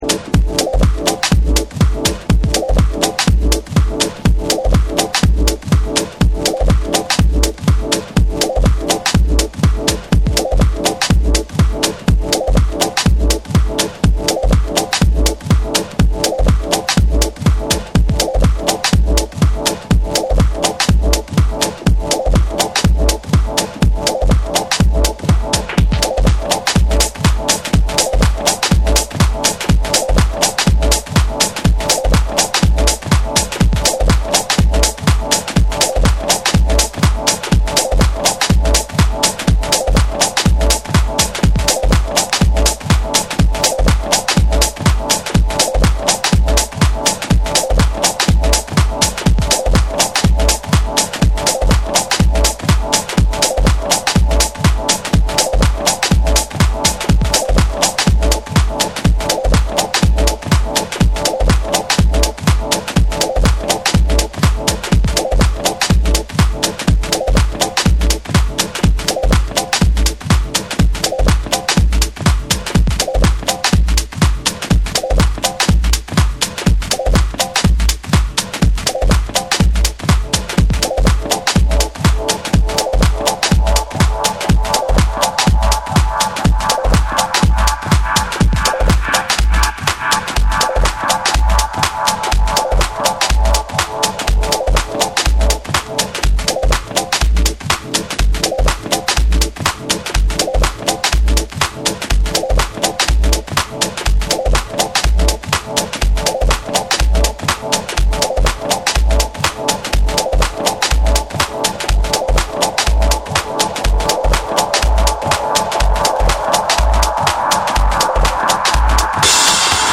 タイトに刻まれるミニマルなビートにパーカッションが絡み、じわじわとビルドアップしていく
緻密に構築されたトライバルなビートが疾走する
ディープかつ機能的なグルーヴでフロアにじわりと効く2曲を収録。
TECHNO & HOUSE / ORGANIC GROOVE